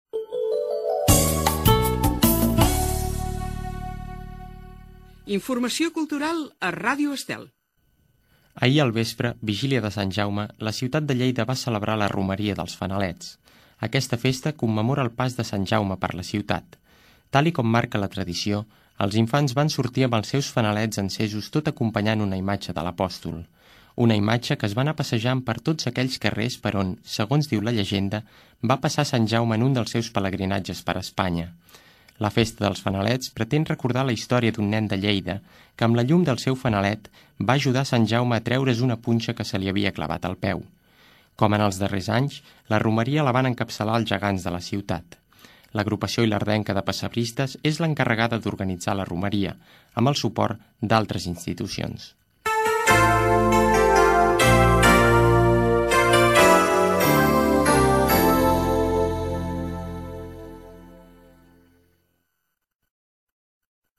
Sintonia de la ràdio, indicatiu del programa, informació de la romaria dels fanalets a Lleida, sintonia de l'emissora
FM